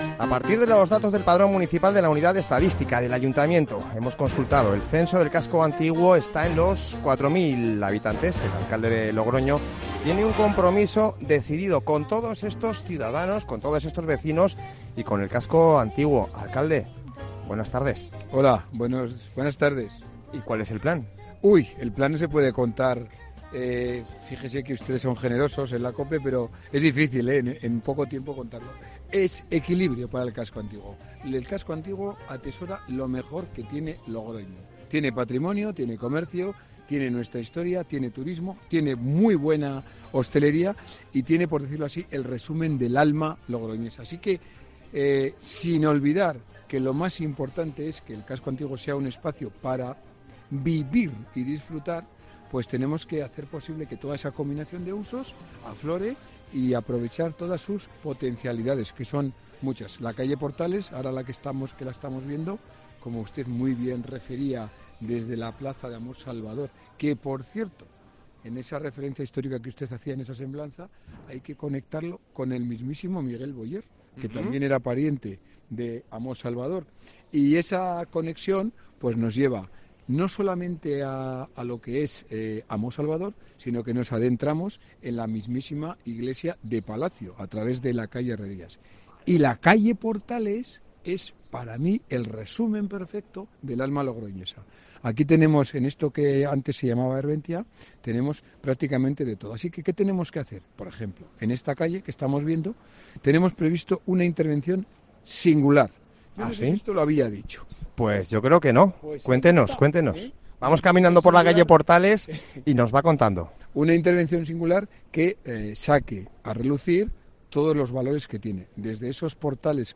El alcalde, Conrado Escobar, explica en los micrófonos de COPE Rioja las medidas más inmediatas que pondrá en marcha en un compromiso decidido con esta zona de la ciudad.